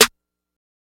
Main Trap Snare.wav